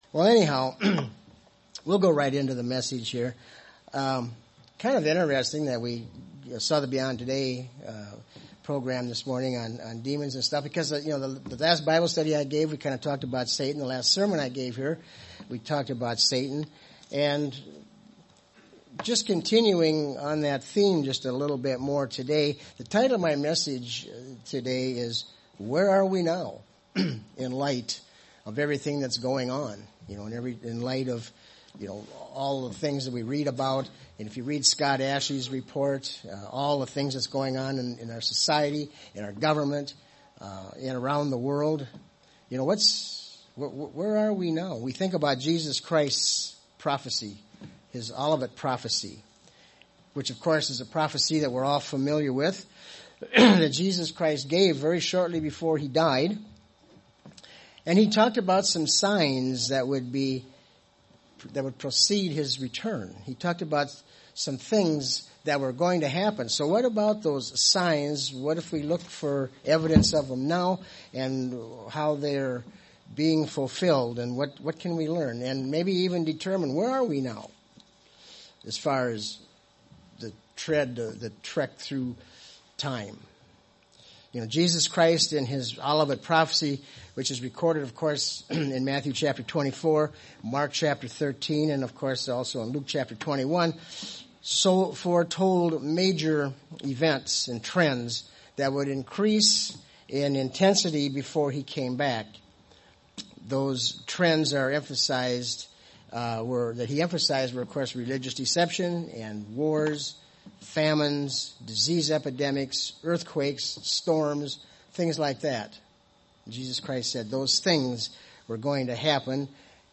In this sermon we will examine where we are in the course of prophetic bible events that signal the end of times.